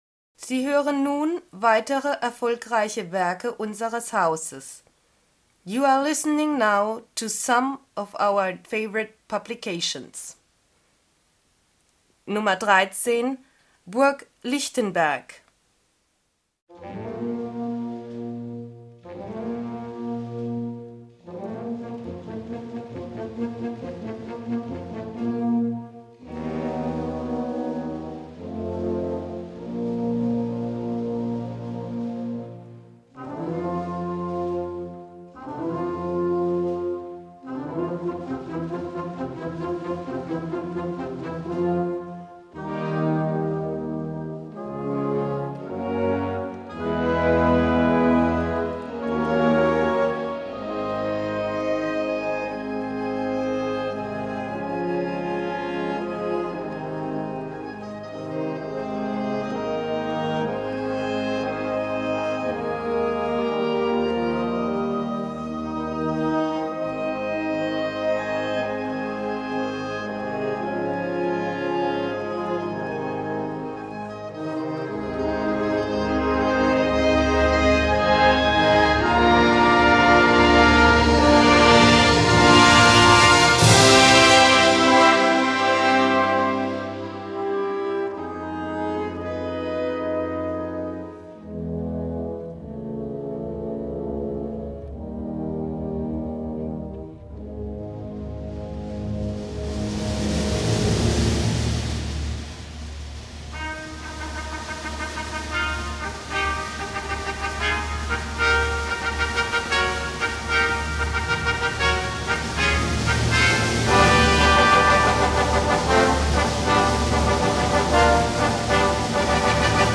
Gattung: Moderne Fantasie
Besetzung: Blasorchester